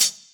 ClosedHH MadFlavor 5.wav